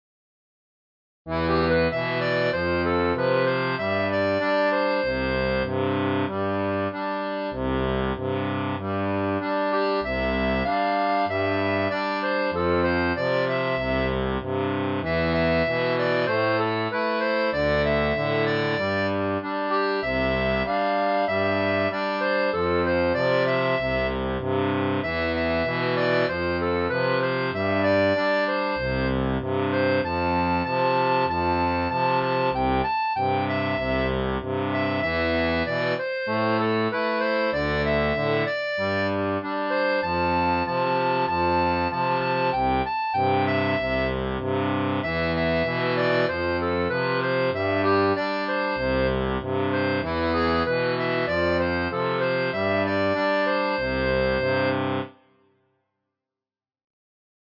• une version pour accordéon diatonique à 2 rangs
Folk et Traditionnel